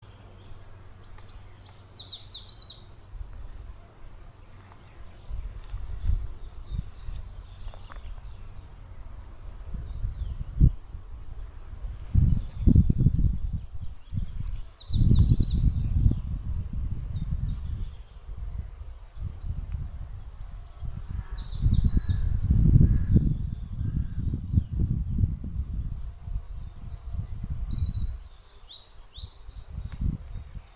Zaļais ķauķītis, Phylloscopus trochiloides
Ziņotāja saglabāts vietas nosaukumsKolka
Zaļais ķauķītis perfekti imitē paceplīti, putns novērots vizuāli dziedam abās dziesmās